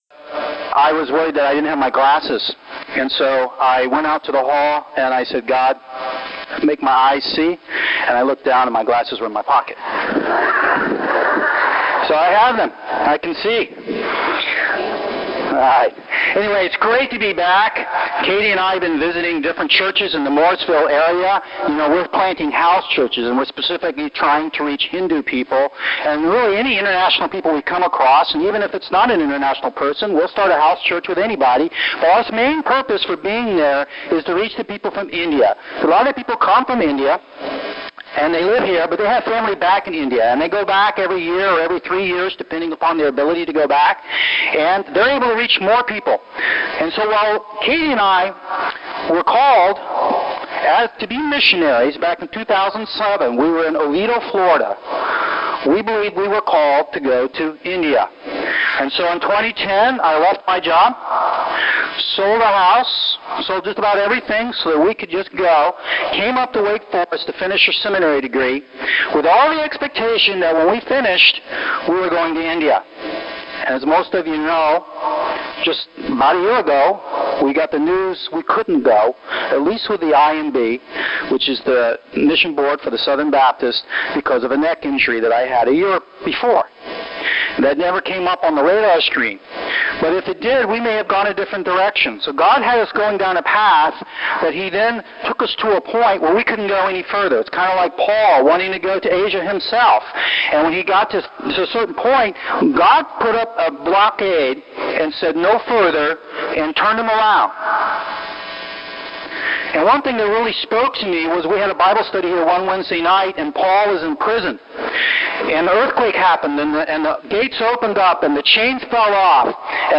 Message given at G3 Fellowship on November 17, 2016
The quality is not great. It is distorted. To completely take out the noise would have made the audio sound more sci-fi like. So the quality provided is a compromise.